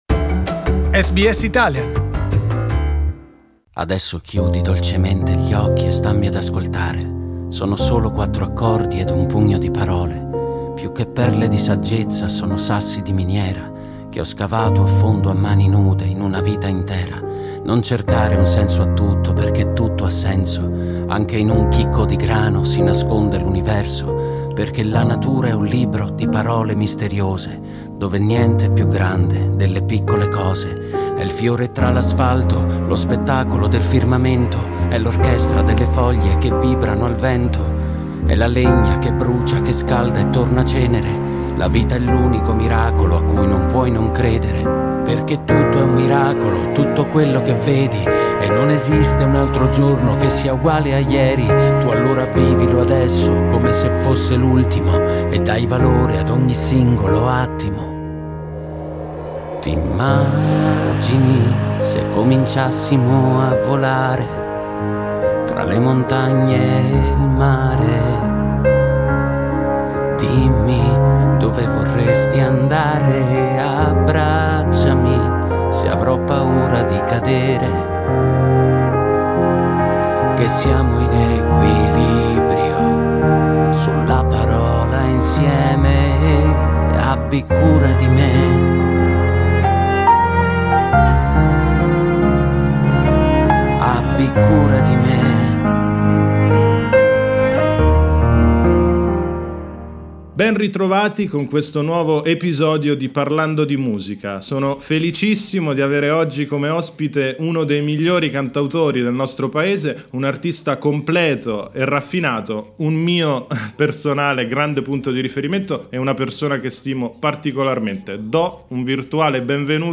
Simone Cristicchi, the 2007 Sanremo music Festival winner, is our guest in the new episode of Parlando di Musica.